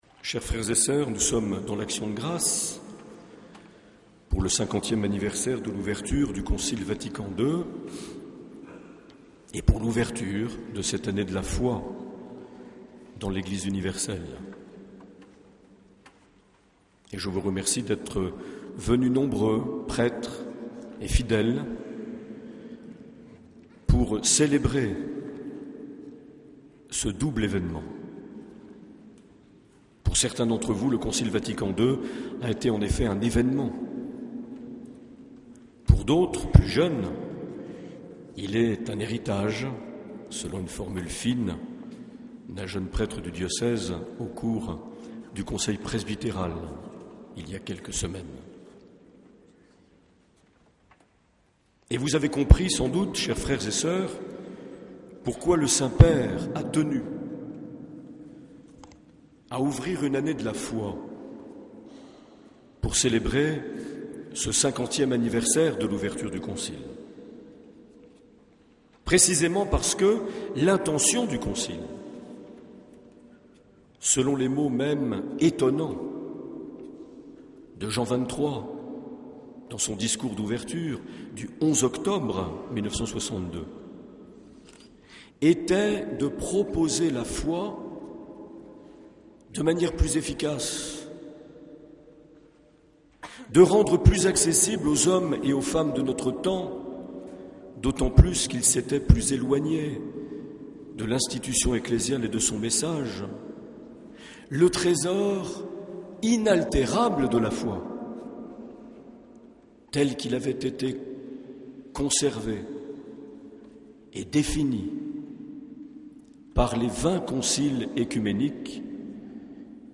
11 octobre 2012 - Cathédrale de Bayonne - Messe de lancement de l’Année de la Foi
Accueil \ Emissions \ Vie de l’Eglise \ Evêque \ Les Homélies \ 11 octobre 2012 - Cathédrale de Bayonne - Messe de lancement de l’Année de la (...)
Une émission présentée par Monseigneur Marc Aillet